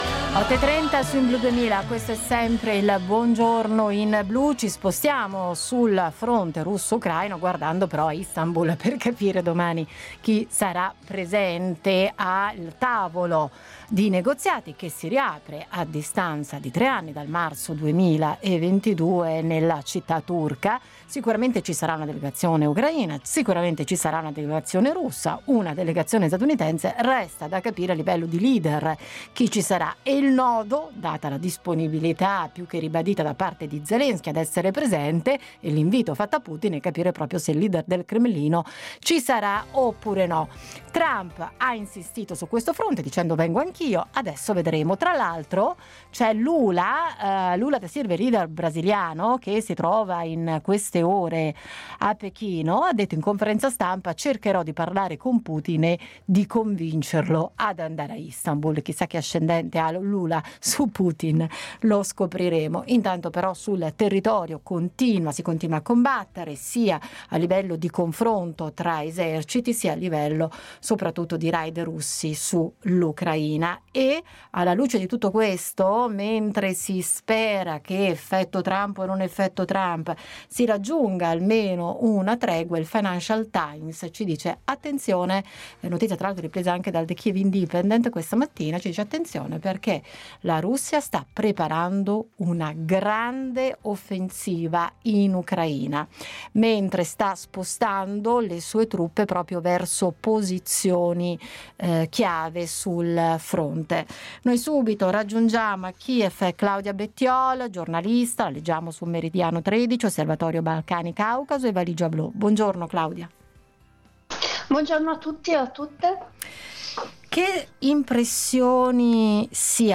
Attesa per l’incontro previsto a Istanbul giovedì 15 maggio per discutere della tregua in Ucraina, con la speranza che ripartano i negoziati. In diretta da Kyiv